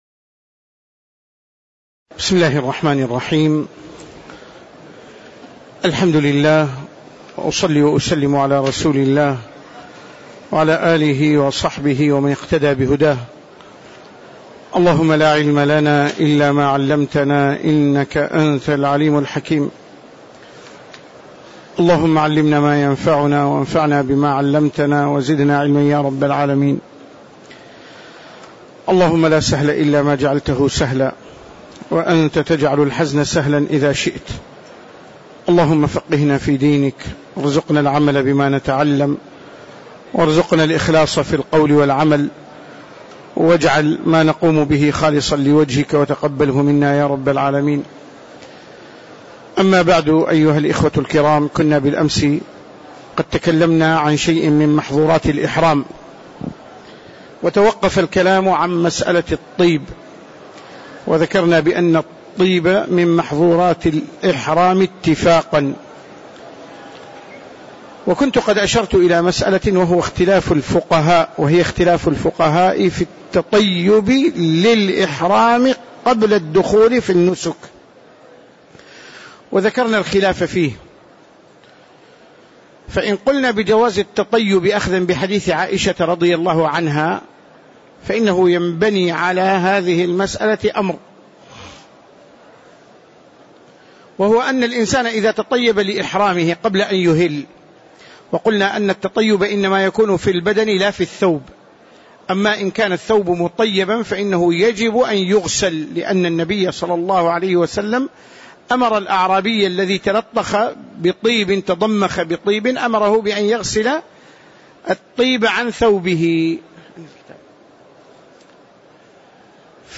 تاريخ النشر ١٣ ذو القعدة ١٤٣٧ هـ المكان: المسجد النبوي الشيخ